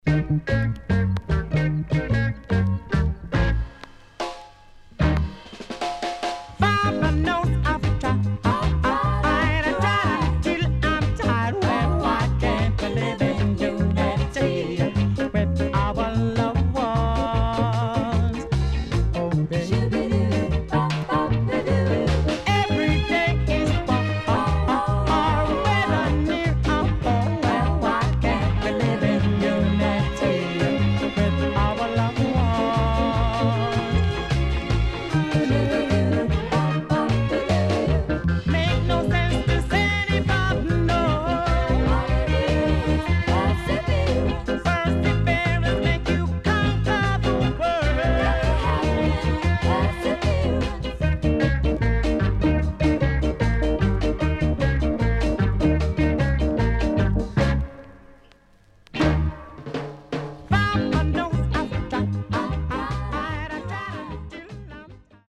HOME > REGGAE / ROOTS  >  定番70’s
SIDE A:少しチリノイズ入りますが良好です。